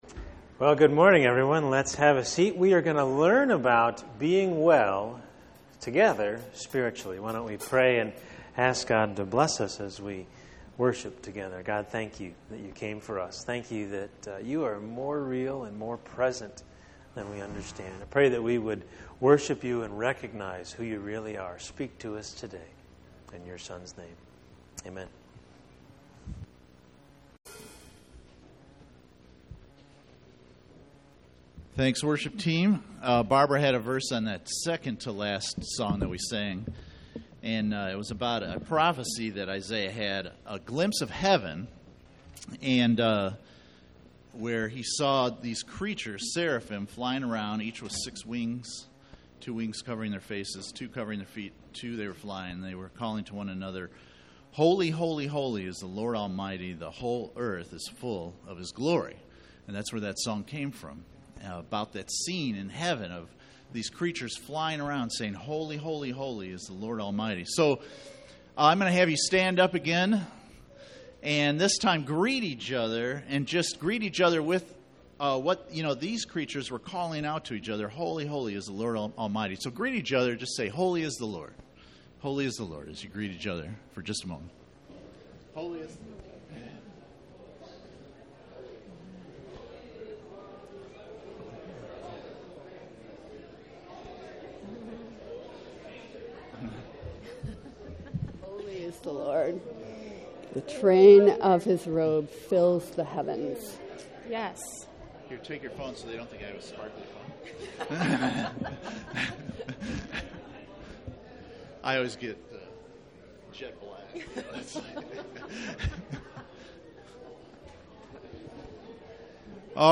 Spiritual Well Being Service Type: Sunday Morning %todo_render% « Spiritual Communication